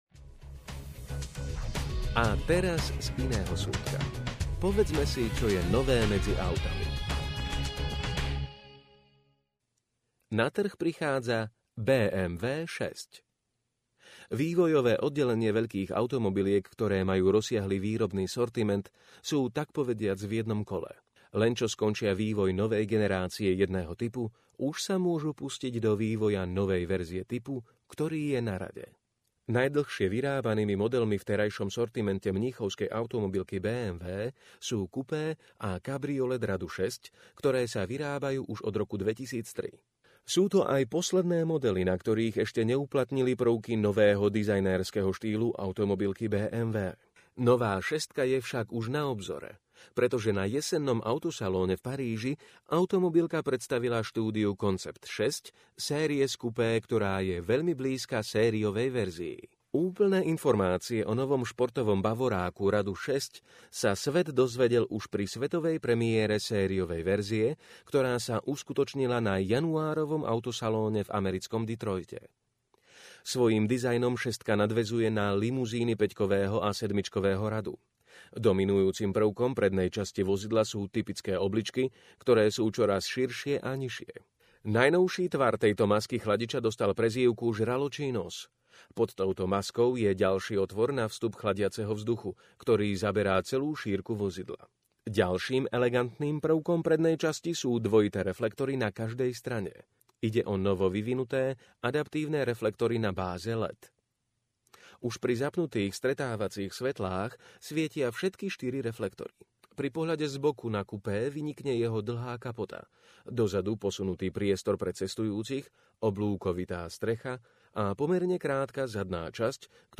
Opäť vás tu čaká obľúbené hodinové čítanie z nového Quarku, aby si mohli vaše oči oddýchnuť.